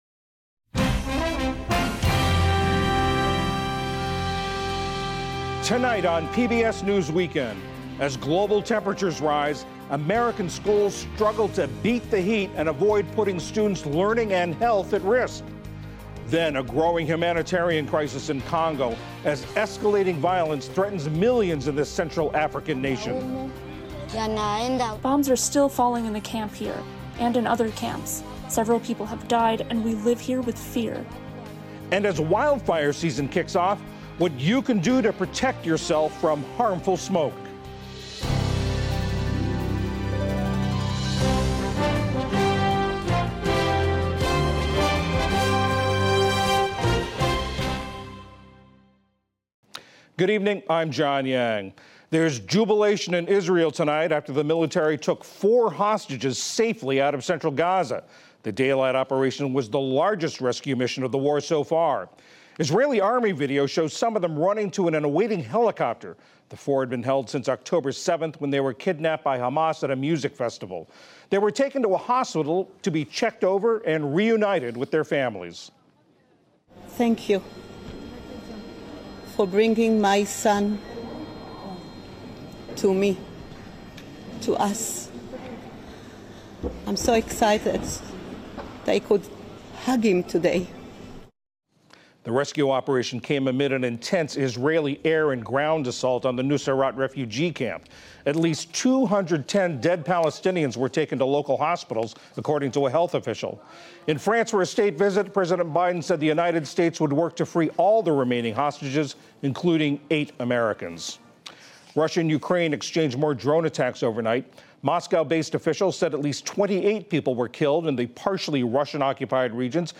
PBS NewsHour News, Daily News